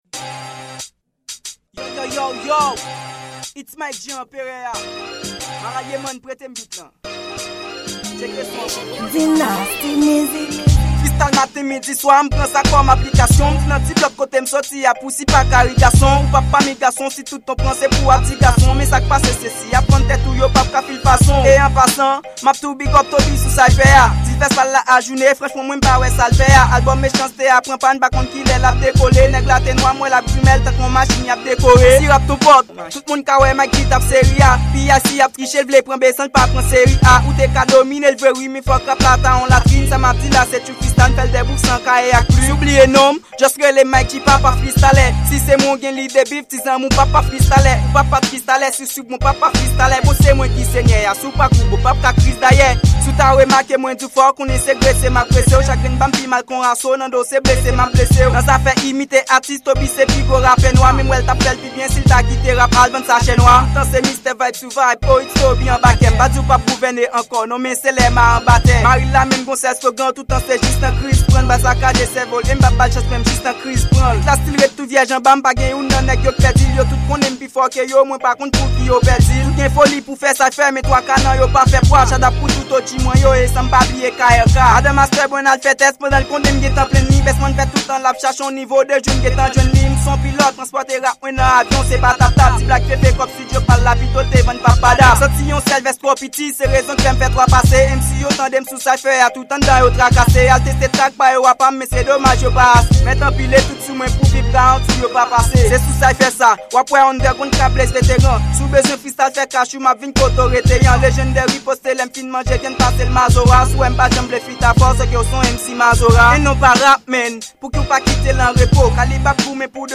Cypher Freestyle
Genre: Rap